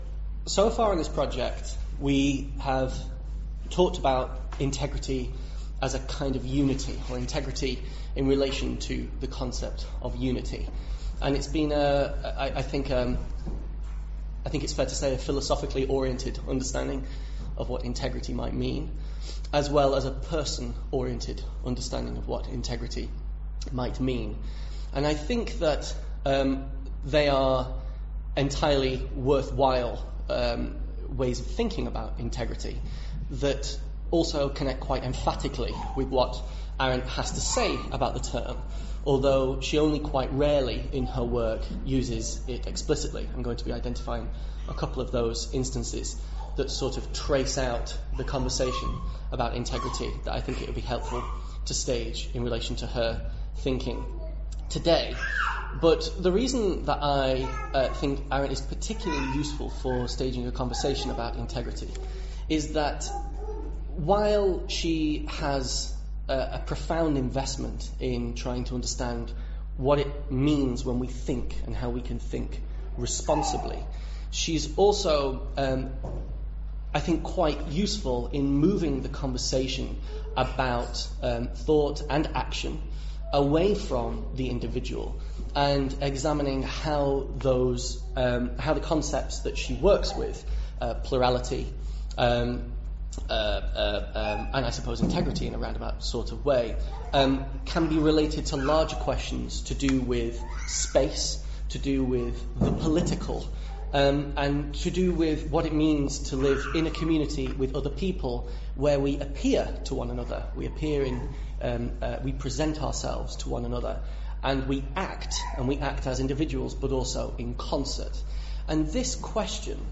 Introductory lecture (mp3 file)